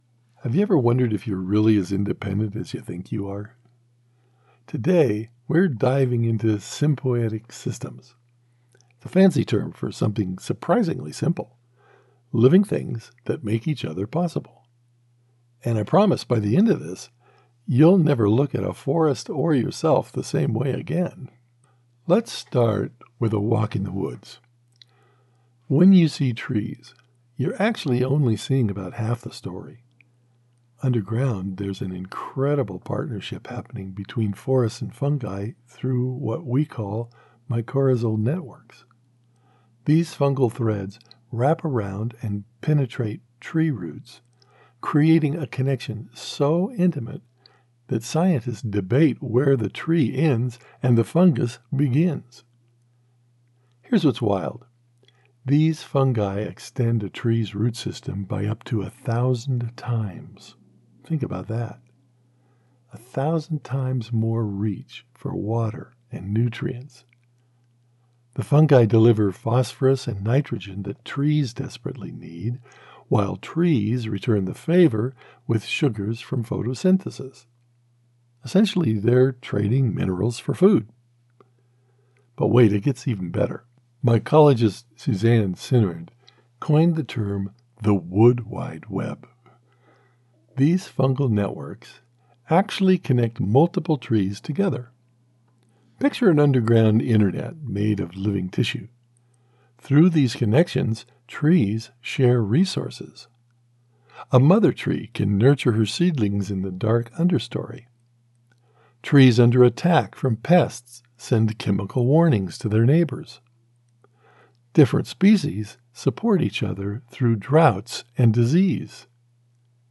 Explainer Narrative - Science
My delivery is sincere, friendly, believable, and natural—the qualities modern projects demand.